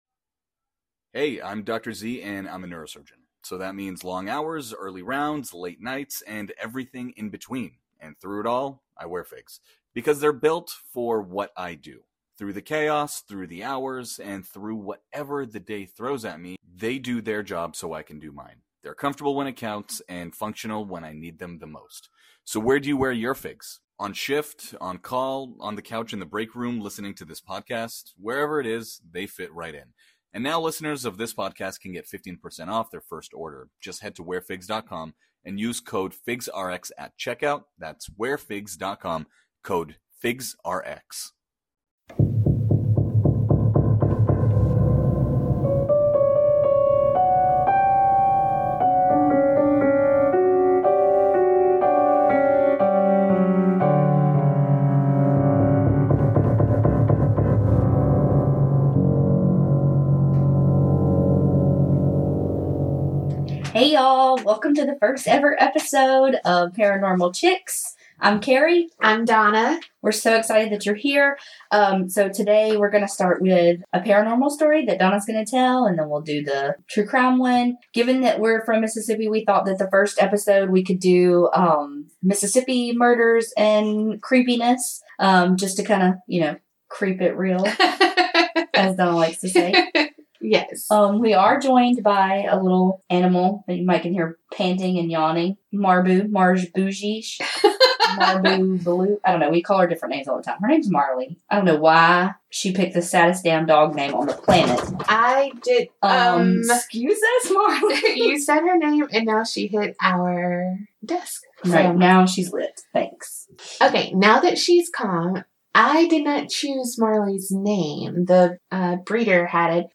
We made mistakes, there’s background noise sometimes (from a dang squeaky chair!).. but it doesn’t take away from what we think is a really good first episode.